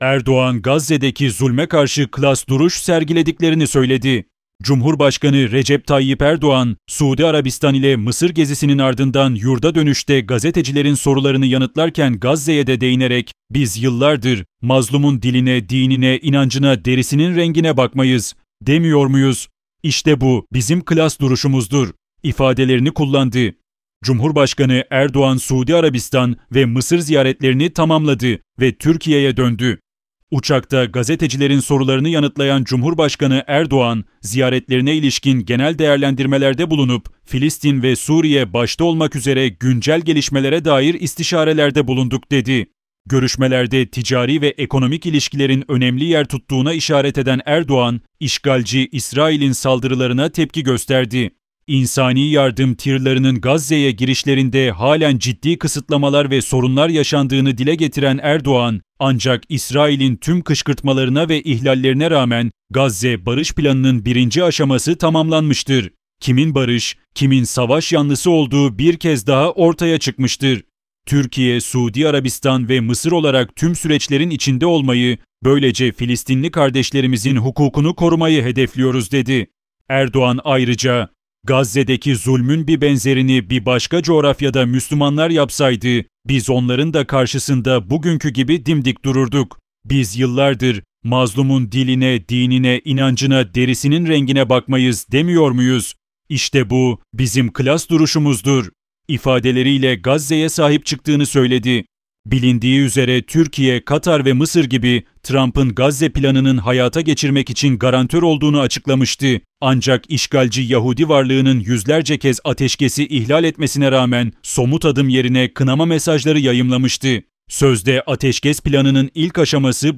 Bu ses yapay zeka tarafından oluşturulmuştur